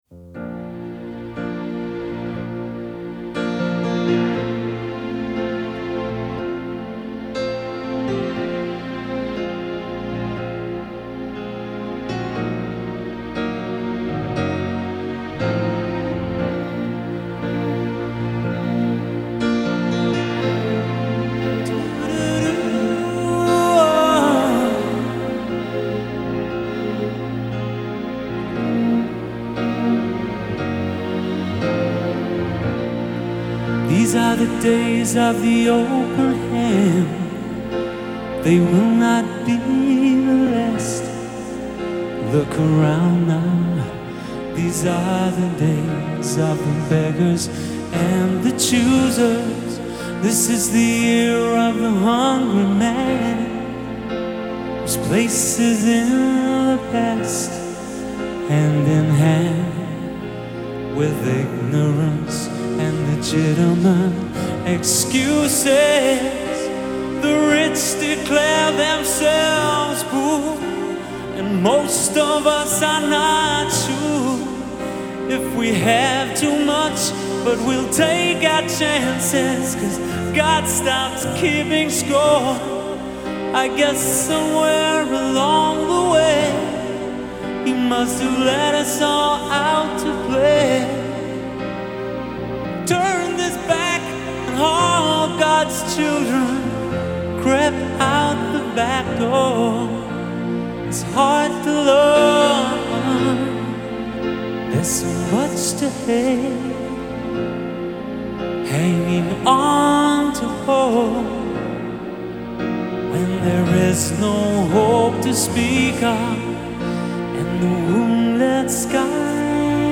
Pop, R&B, Soul, Pop Rock